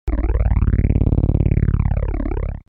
دانلود آهنگ هلیکوپتر 12 از افکت صوتی حمل و نقل
دانلود صدای هلیکوپتر 12 از ساعد نیوز با لینک مستقیم و کیفیت بالا
جلوه های صوتی